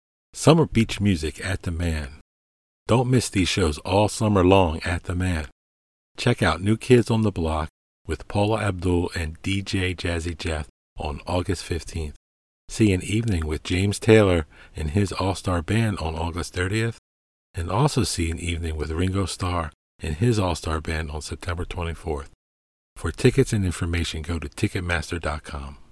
Voice Over Commercial Demos: Medicare Summer Beach Shows - Ticket Master